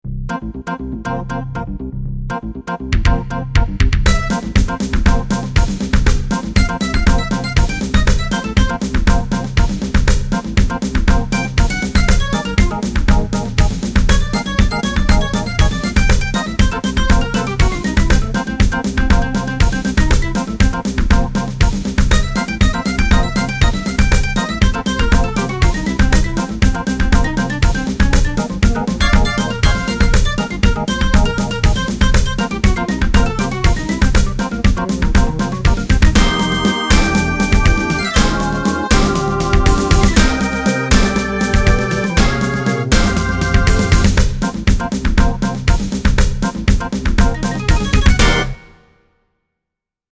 MSC-09S; MPC-09S Organ